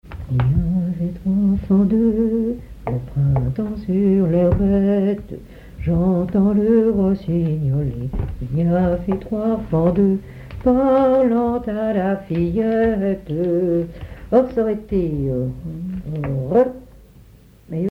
Sainte-Hélène-Bondeville
Genre strophique
Pièce musicale inédite